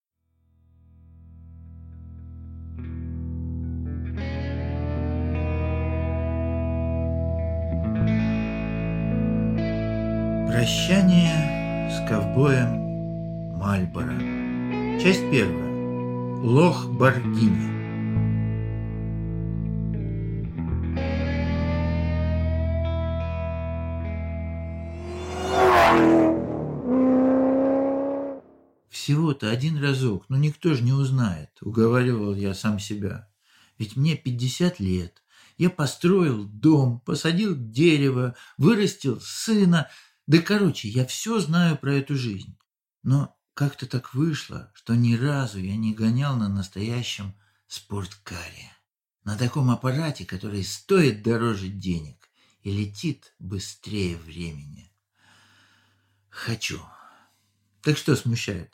Аудиокнига Прощание с ковбоем мальборо | Библиотека аудиокниг